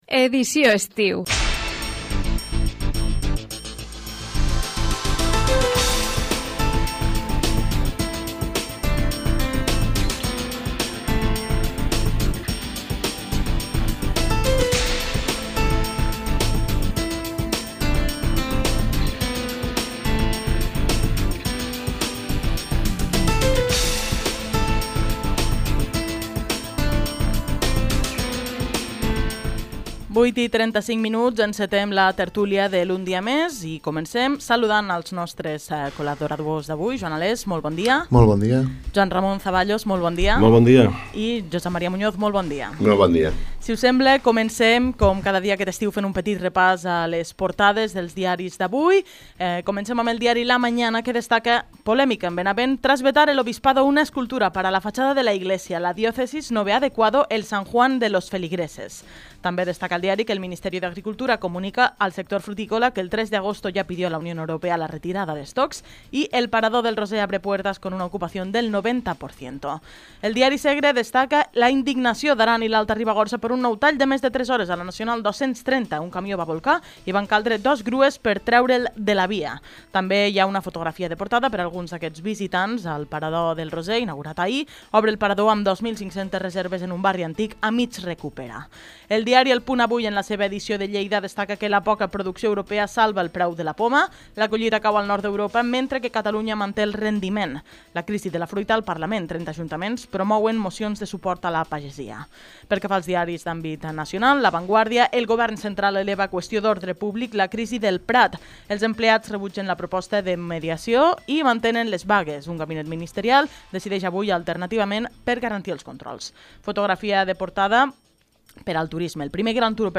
Tertúlia de ràdio UA1.